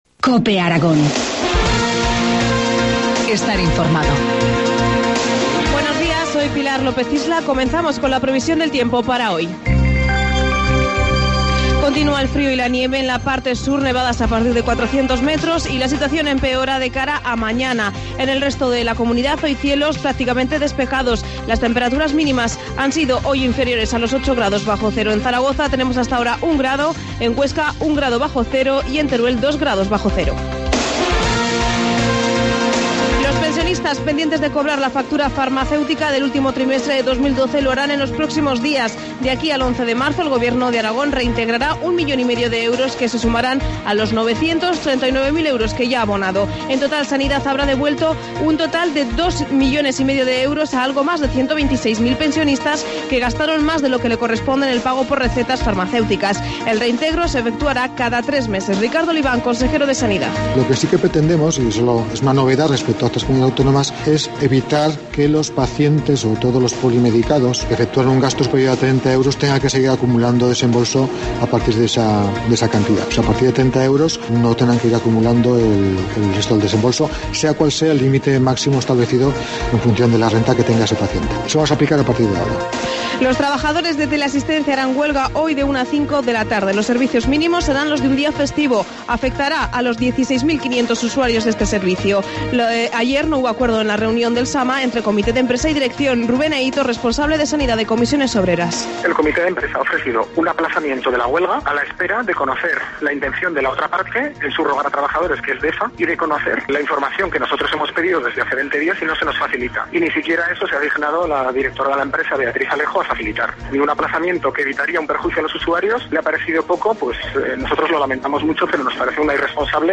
Informativo matinal, 27 de febrero, 8.25 horas